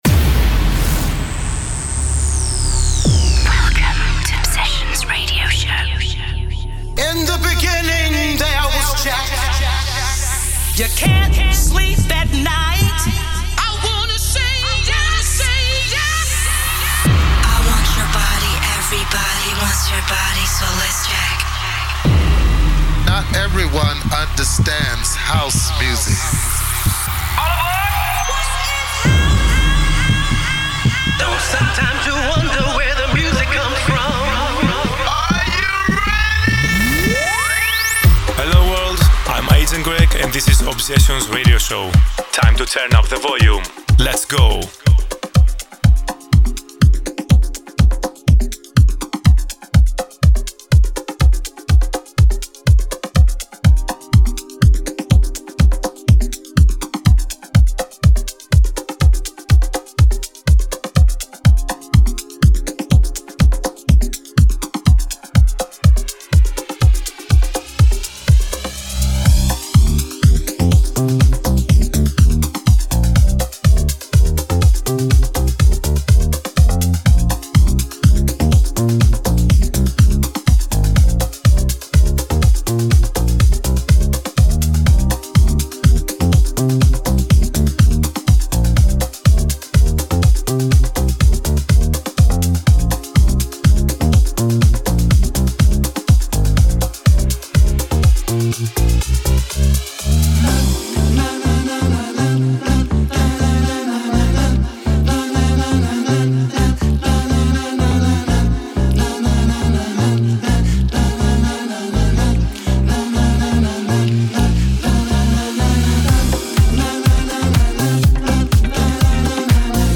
weekly 1 hour music mix
Expect nothing but pure House music.